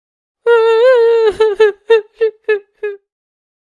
grandma cry sound.
cry.ogg